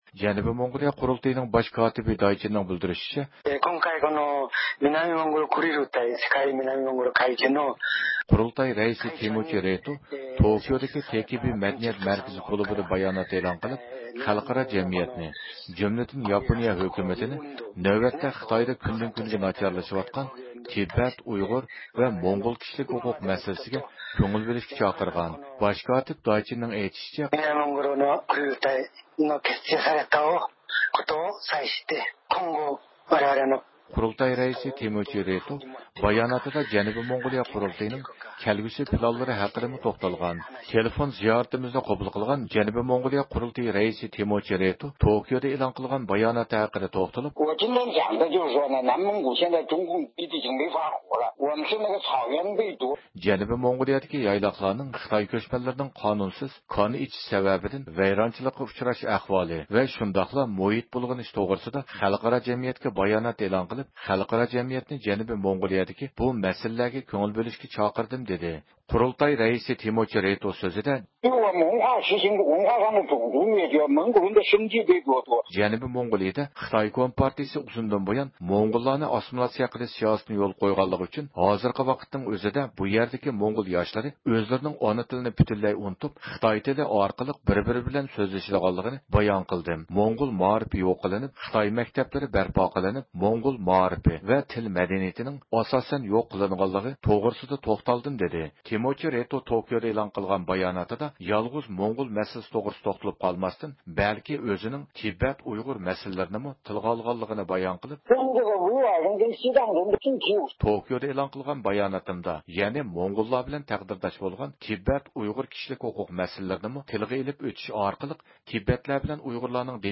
تېلېفون زىيارىتىمىزنى قوبۇل قىلغان